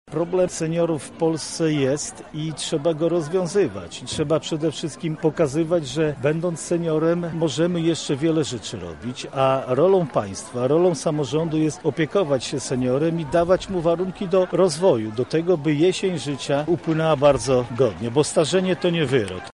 W Muzeum Wsi Lubelskiej świętowano Wojewódzki Dzień Rodziny.
• mówi marszałek województwa lubelskiego Jarosław Stawiarski